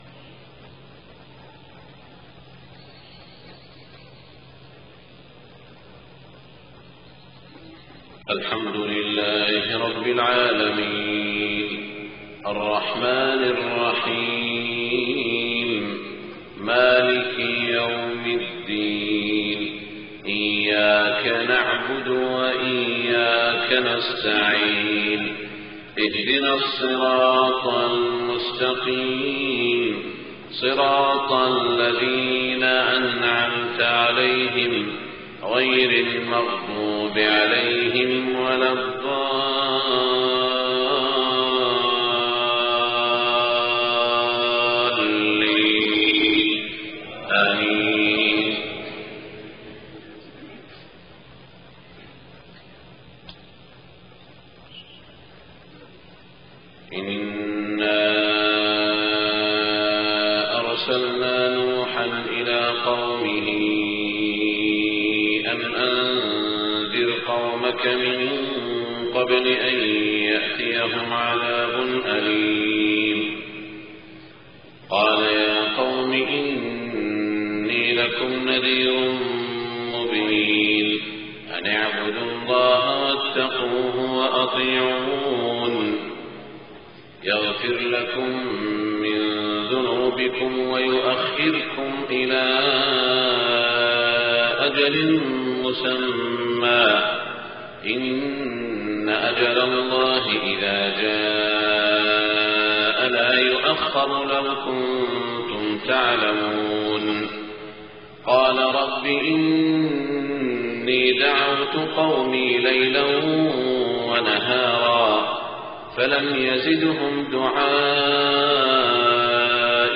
صلاة الفجر 8-5-1427 من سورة نوح > 1427 🕋 > الفروض - تلاوات الحرمين